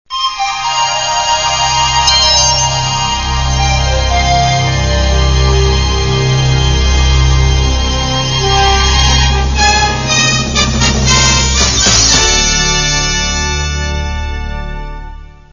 Première Ident Standard Ident